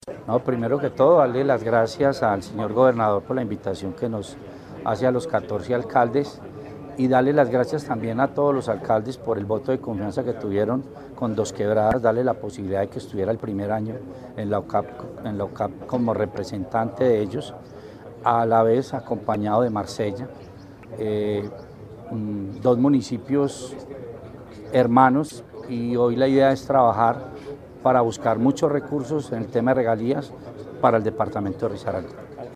Escuchar Audio Alcalde de Marsella, Alberto Peláez Hernández